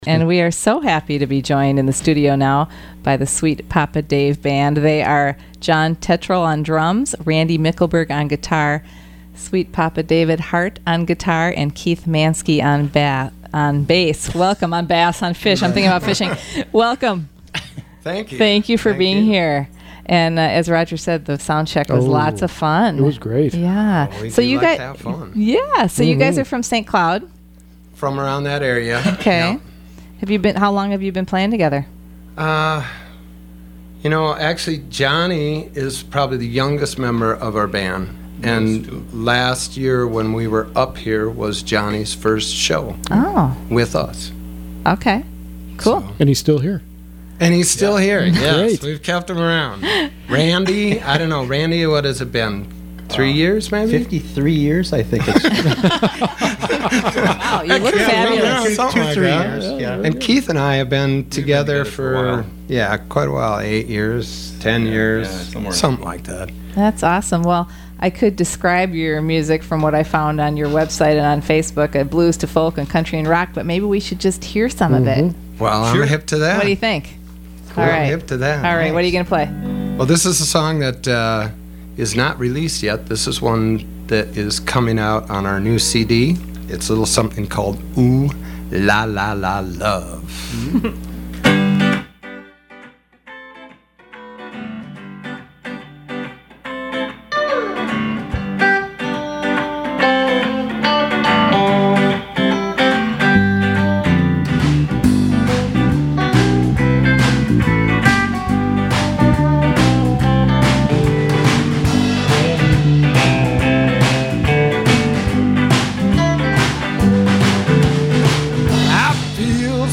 in studio
guitar
bass
drums